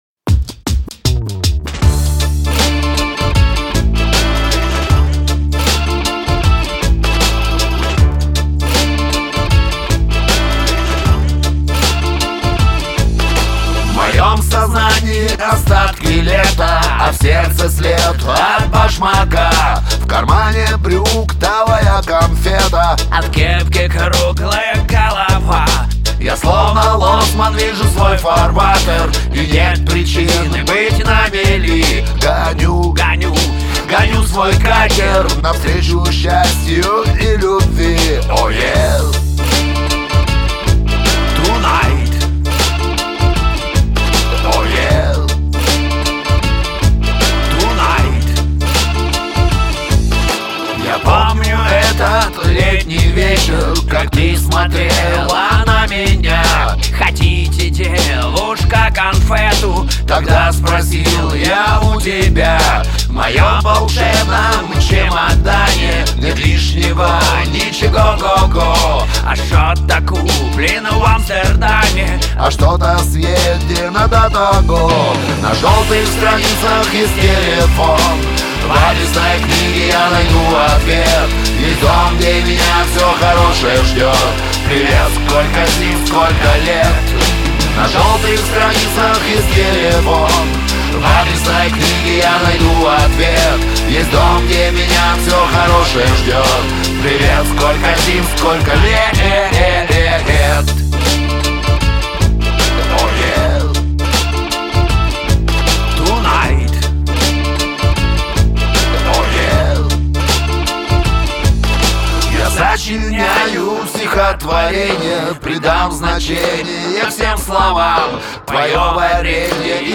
шансон 2014